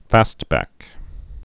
(făstbăk)